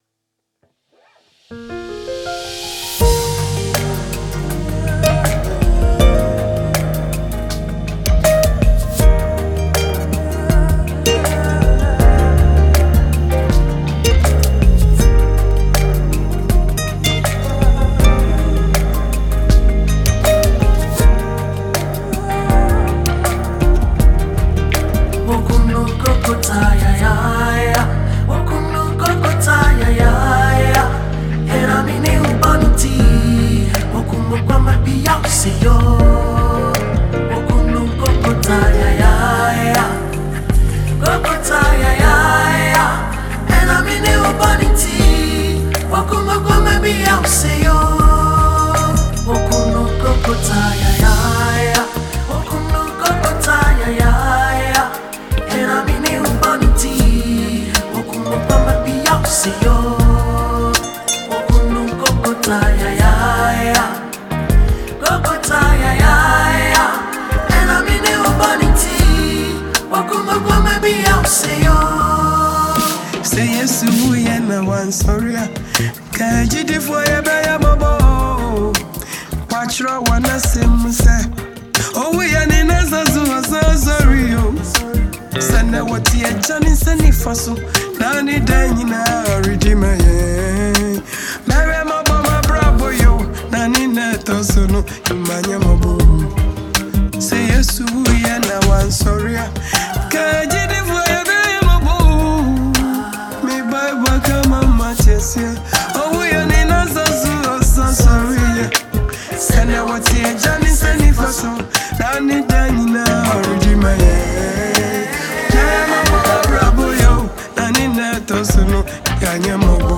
Ghana Gospel Music